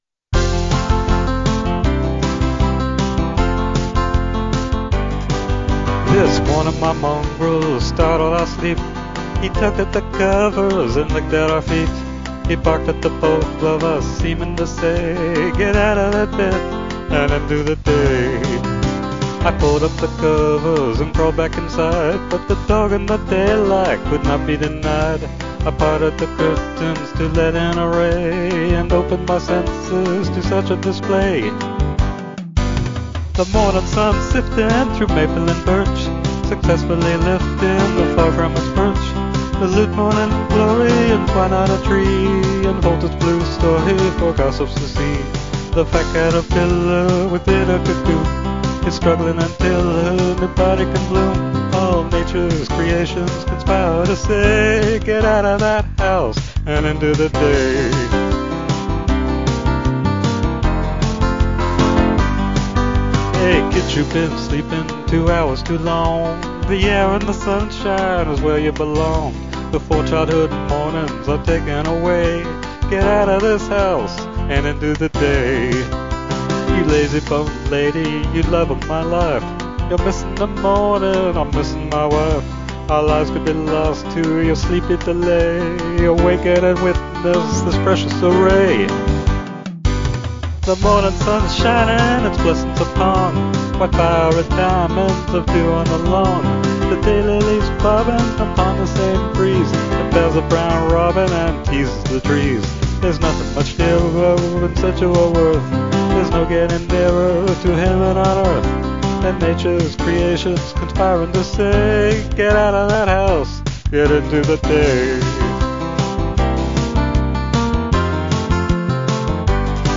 upbeat syncopated 4/4 pop, male voice